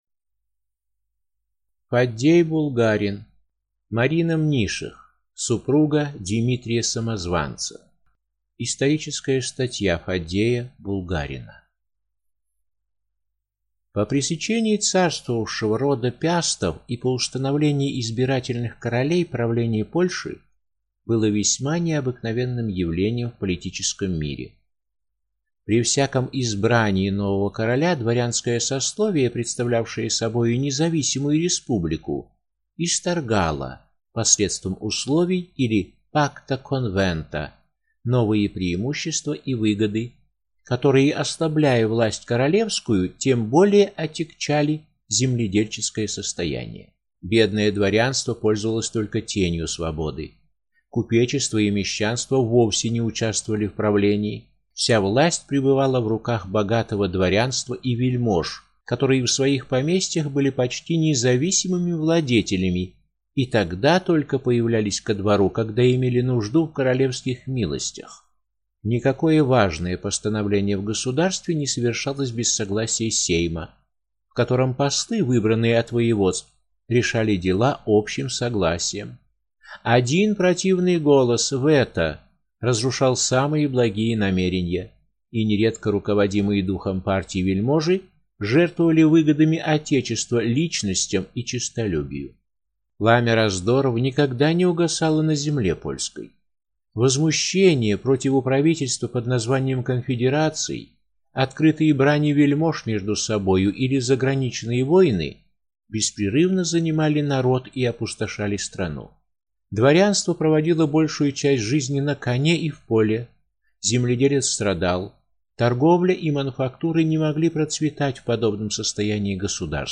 Аудиокнига Марина Мнишех, супруга Димитрия Самозванца | Библиотека аудиокниг